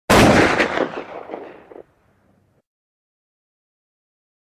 Ak-47 Single Shot